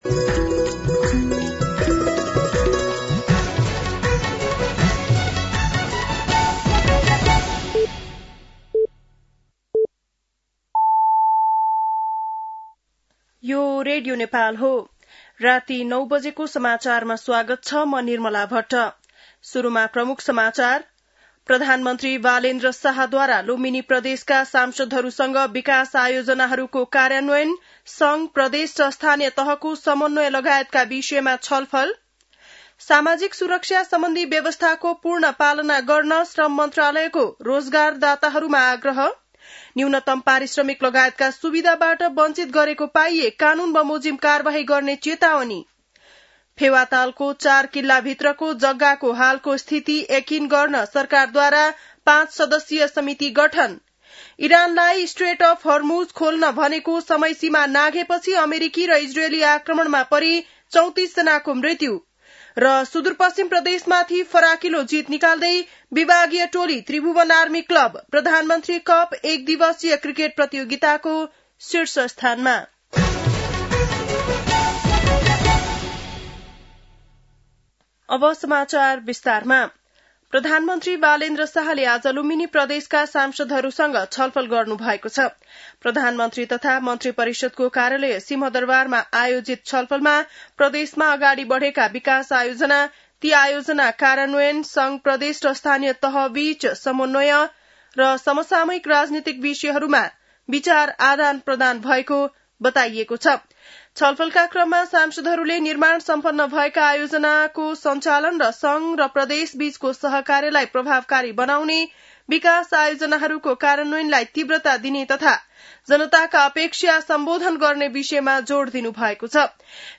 बेलुकी ९ बजेको नेपाली समाचार : २३ चैत , २०८२
9-PM-Nepali-NEWS-12-23.mp3